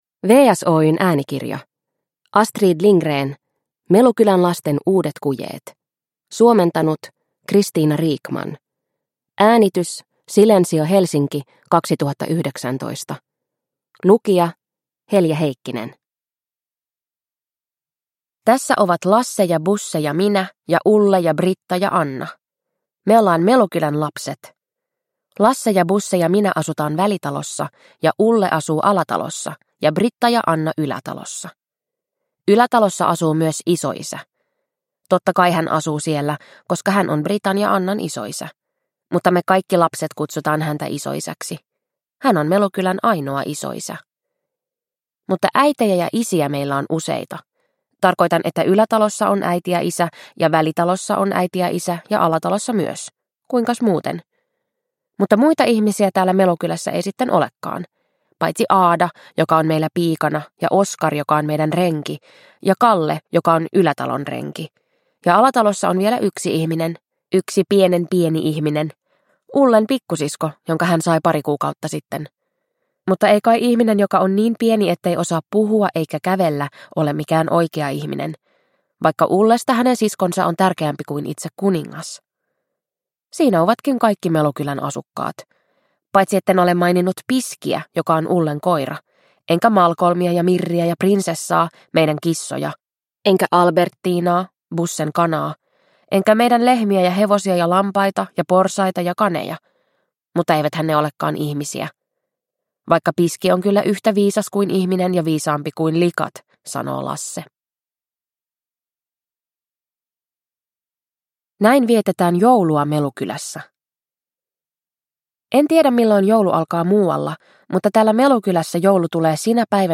Melukylän lasten uudet kujeet – Ljudbok – Laddas ner